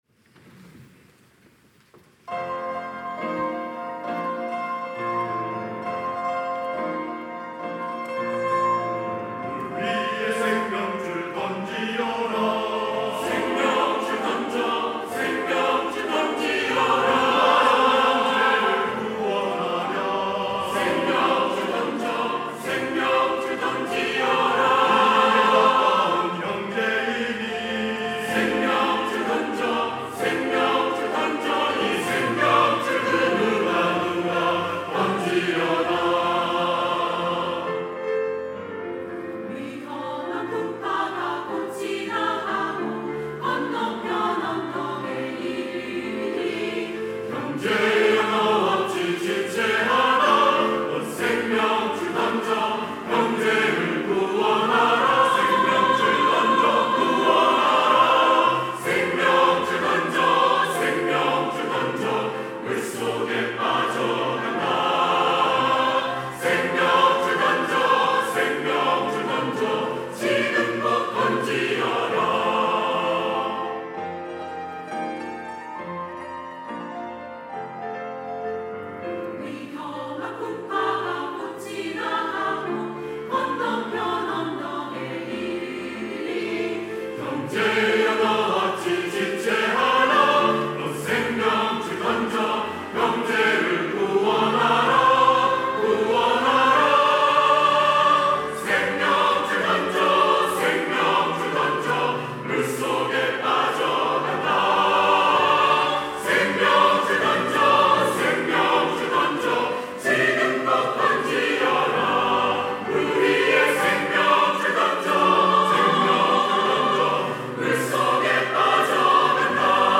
할렐루야(주일2부) - 물 위에 생명줄 던지어라
찬양대